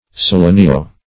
Search Result for " selenio-" : The Collaborative International Dictionary of English v.0.48: Selenio- \Se*le"ni*o-\ (Chem.) A combining form (also used adjectively) denoting the presence of selenium or its compounds; as, selenio-phosphate, a phosphate having selenium in place of all, or a part, of the oxygen.